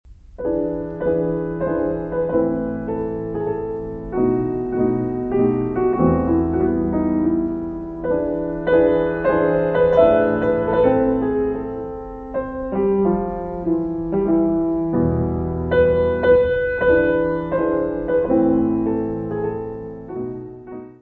piano
Music Category/Genre:  Classical Music